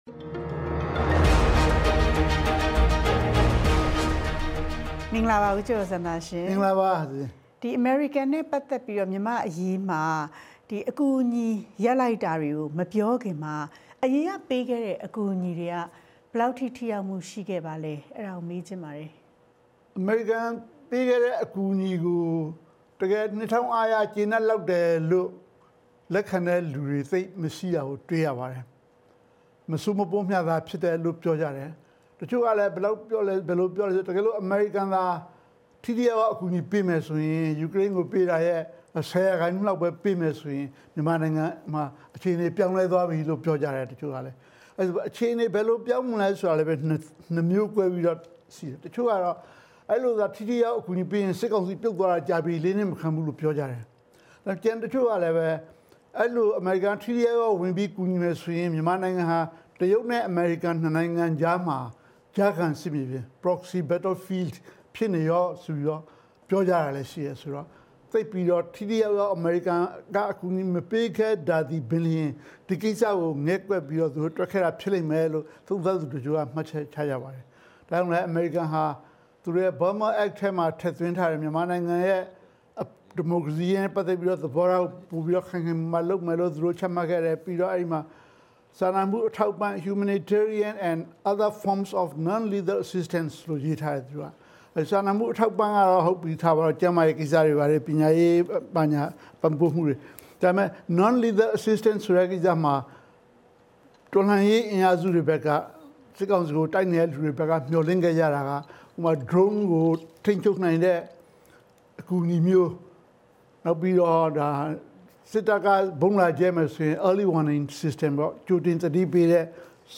မေးမြန်းဆွေးနွေးထားပါတယ်။